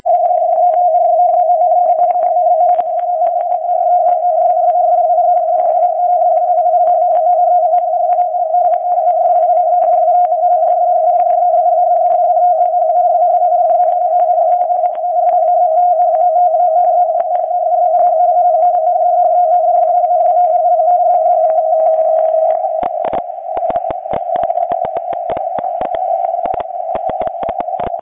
�udio VLF
18khz_tty_shift_83hz.mp3